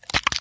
Add fracture sound effects
fracture_1.wav